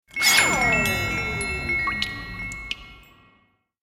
دانلود آهنگ آب 20 از افکت صوتی طبیعت و محیط
جلوه های صوتی
دانلود صدای آب 20 از ساعد نیوز با لینک مستقیم و کیفیت بالا